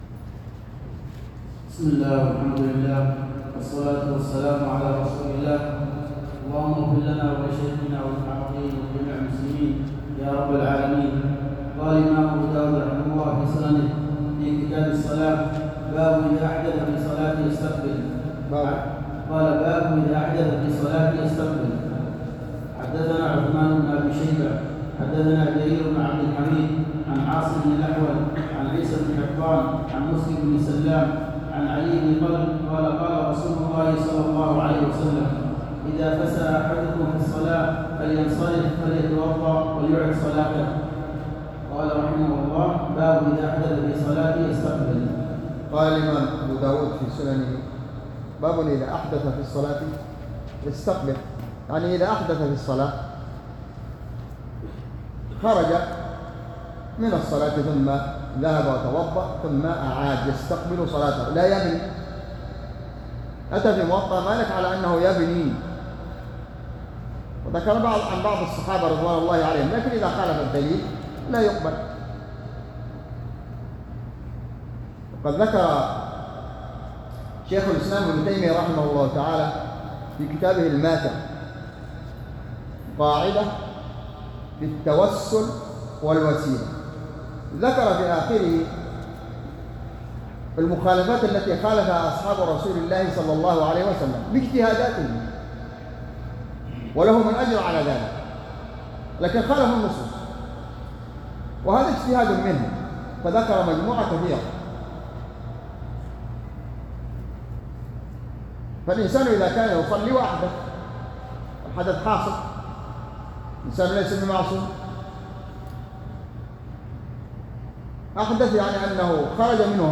شرح كتاب الصلاة - سنن أبي داود | ١٦ جمادى الأولى ١٤٤٤ هـ _ بجامع الدرسي صبيا